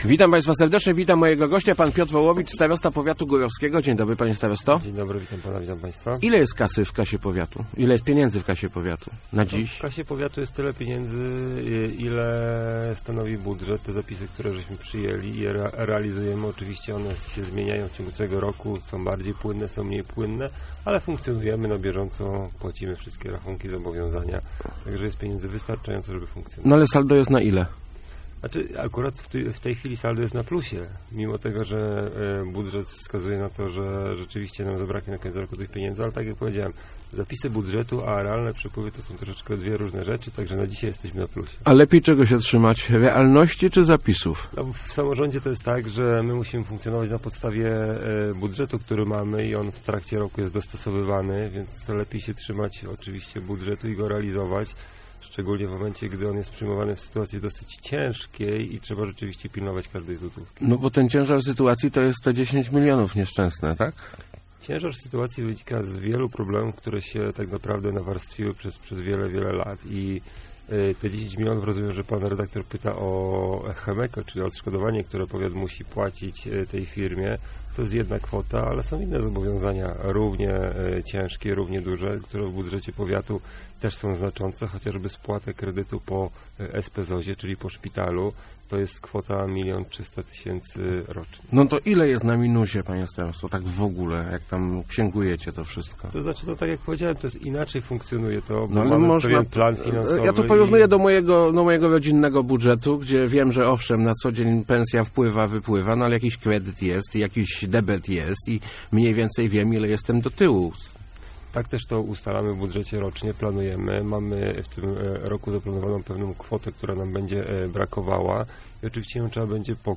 Sprzedaż szpitala nie uratuje budżetu powiatu - przyznał w Rozmowach Elki Piotr Wołowicz, starosta górowski. Podkreślił on, że decyzja miała na celu zapewnienie funkcjonowania placówki.